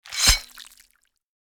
Download Melee Weapon sound effect for free.
Melee Weapon